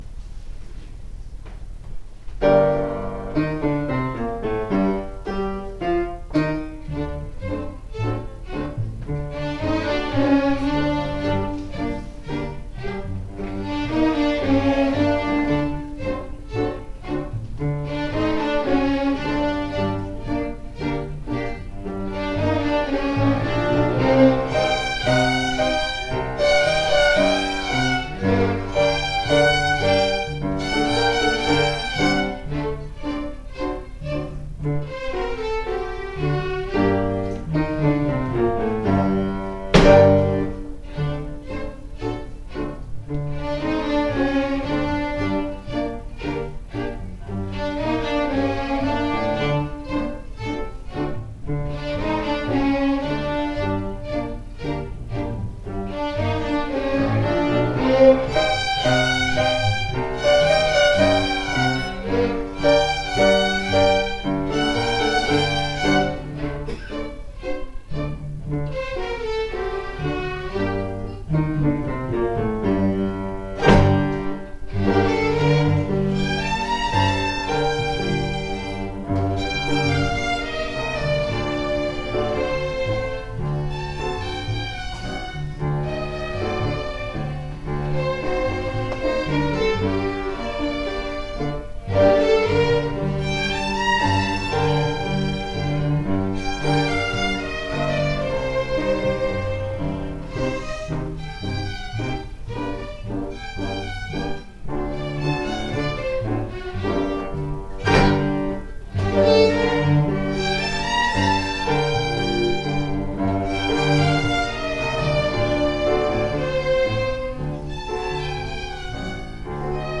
ny stråkorkester i Vasa Viulunsoitinorkesteri Vaasassa
LÄNKAR TILL EXEMPEL PÅ ORKESTERNS FRAMFÖRANDE